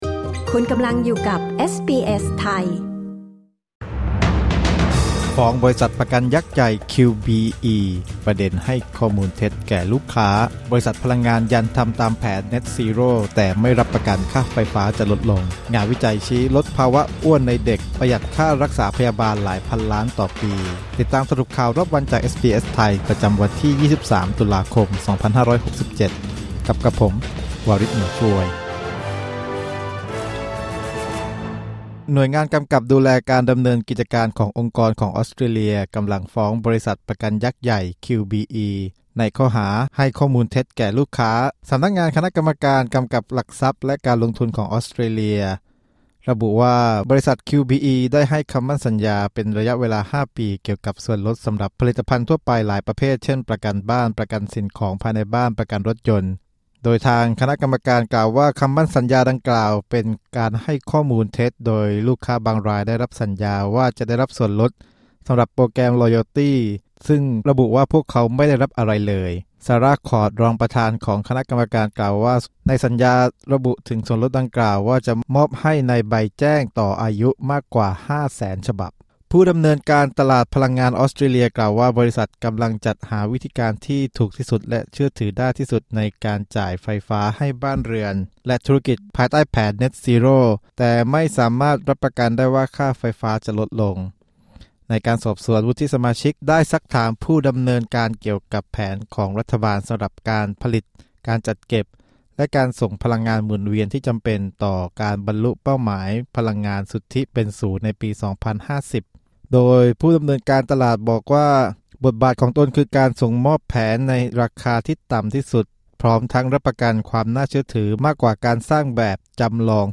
สรุปข่าวรอบวัน 23 ตุลาคม 2567